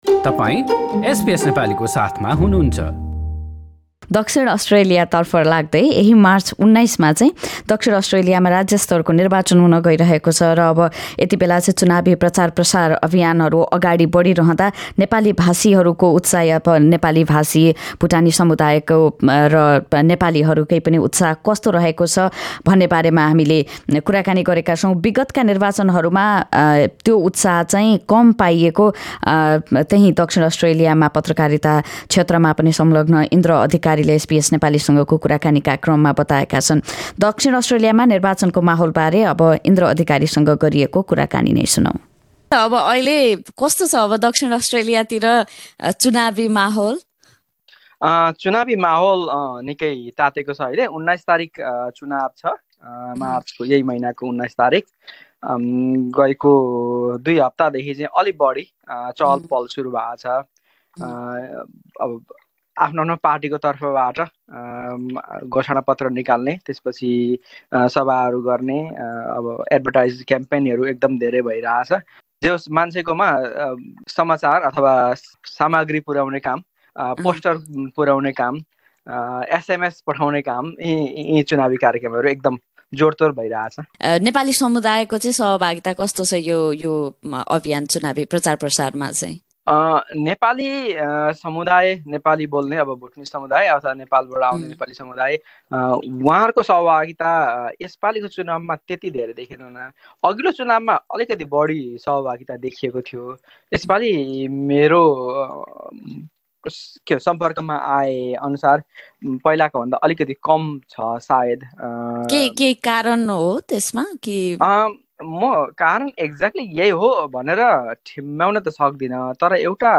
एसबीएस नेपालीसँगको कुराकानी।